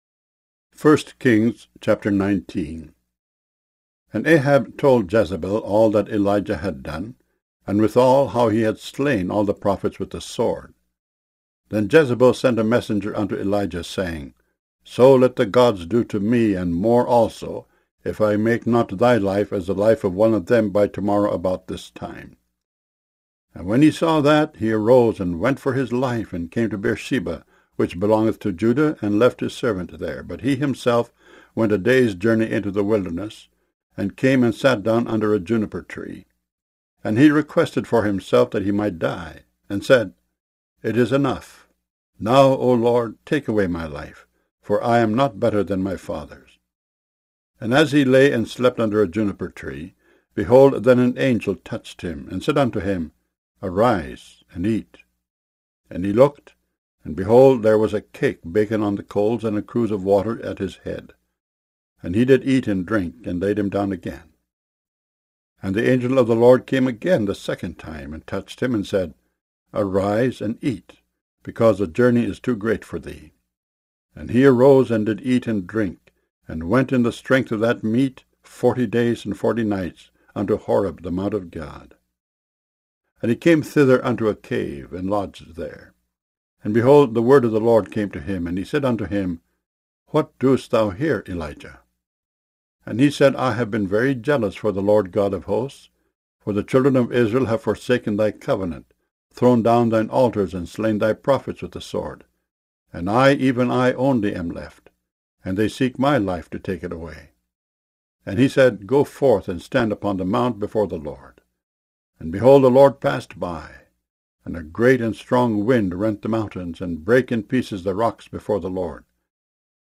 KJV Bible Chapters Mono MP3 64 KBPS